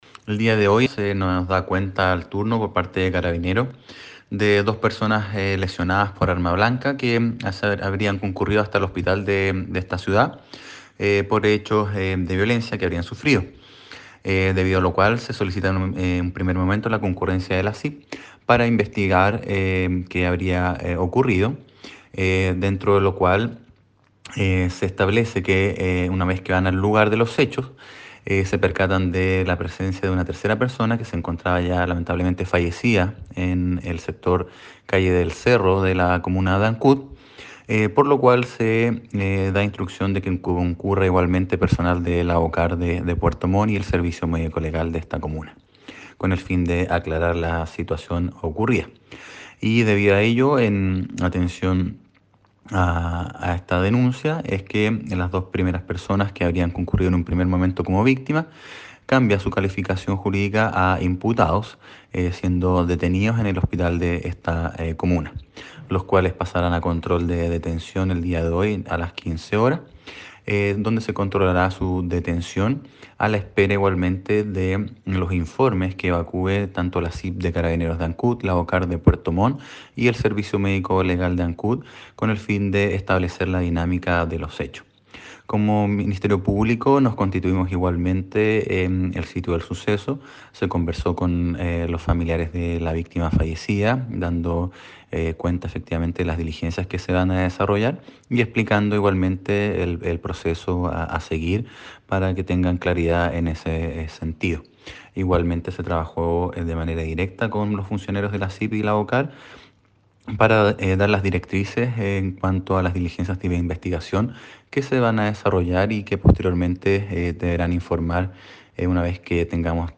Escuchemos los pormenores que entregó el fiscal de Ancud, Luis Barría, quien señala que existen dos personas imputadas del posible delito de homicidio, por cuanto en primera instancia llegaron heridas por arma blanca a la urgencia del Hospital de Ancud.
25-FISCAL-LUIS-BARRIA-POR-ANCUD.mp3